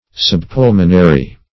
Meaning of subpulmonary. subpulmonary synonyms, pronunciation, spelling and more from Free Dictionary.
Search Result for " subpulmonary" : The Collaborative International Dictionary of English v.0.48: Subpulmonary \Sub*pul"mo*na*ry\, a. (Anat.) Situated under, or on the ventral side of, the lungs.